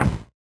CS_VocoBitB_Hit-11.wav